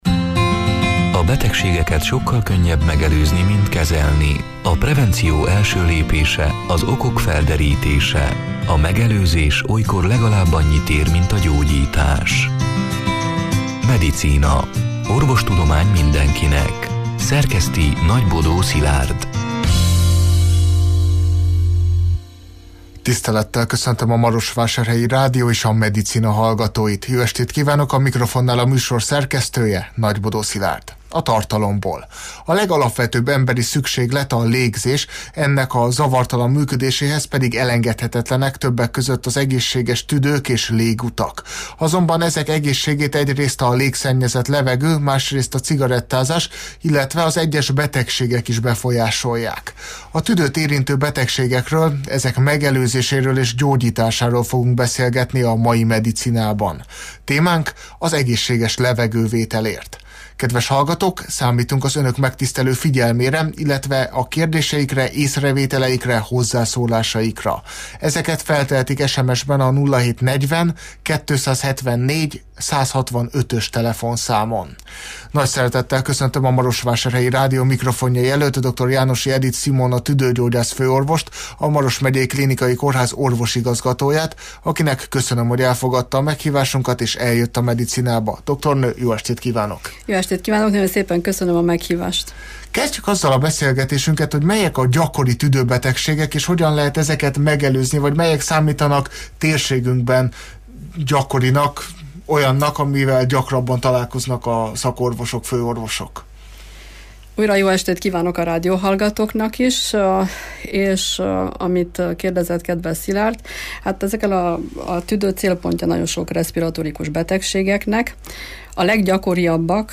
A Marosvásárhelyi Rádió Medicina (elhangzott: 2025. április 9-én, szerdán este nyolc órától élőben) c. műsorának hanganyaga: